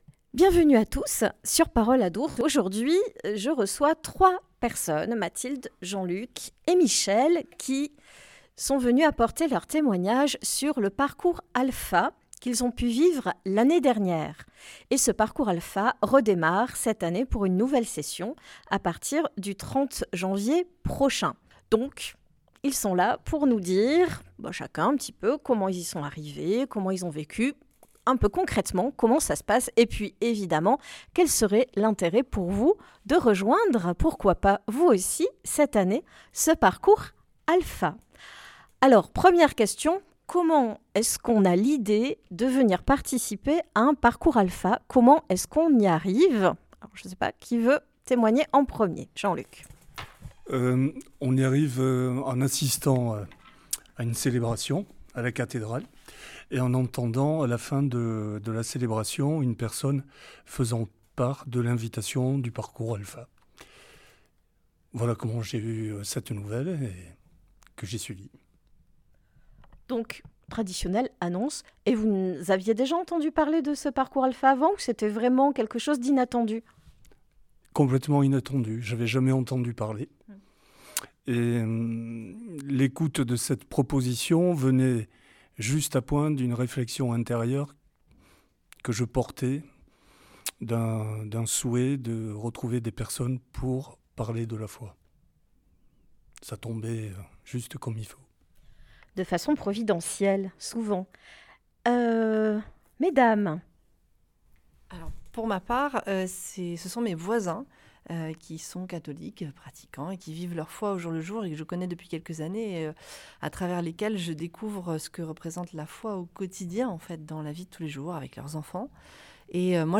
Ecoutez le témoignage de trois participants de l’édition 2025 :
parcours-alpha-temoignages.mp3